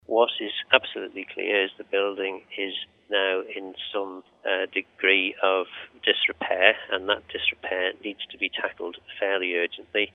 Phil Gawne has spoken out after plans for refurbishment work caused alarm with the public: